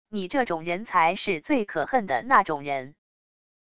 你这种人才是最可恨的那种人wav音频生成系统WAV Audio Player